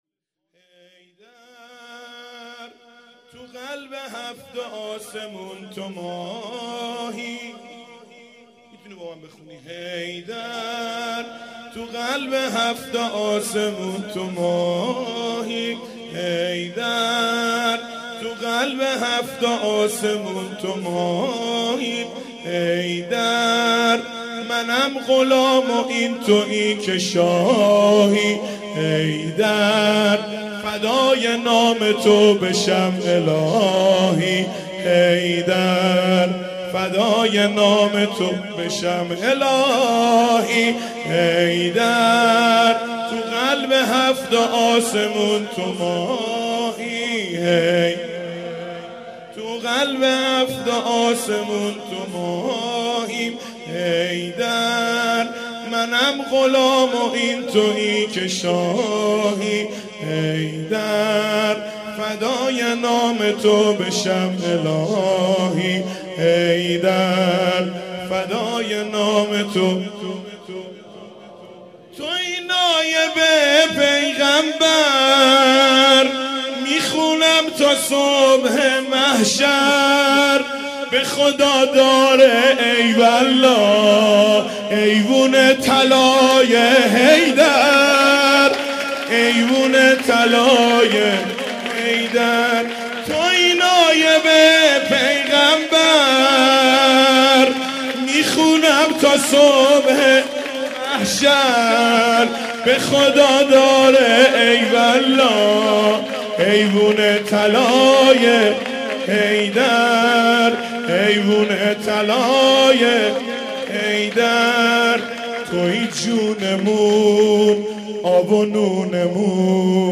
جشن میلاد حضرت علی(ع)/ریحانه النبی(س)